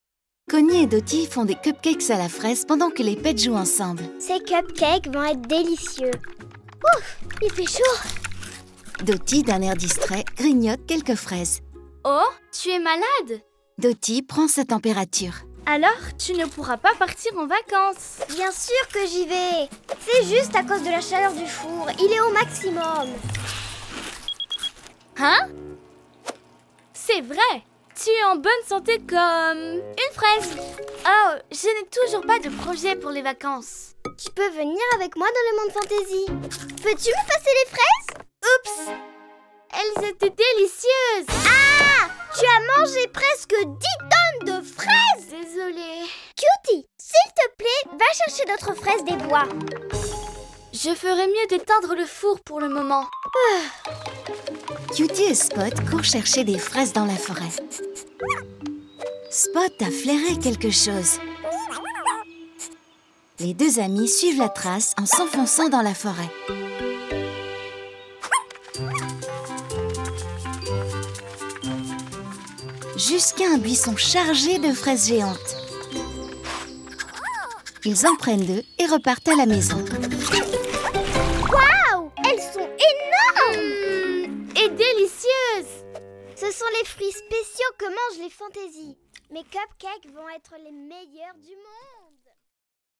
Découvrez les nouveaux livres audio pleins d'aventures et de fantaisies !